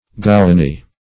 gowany.mp3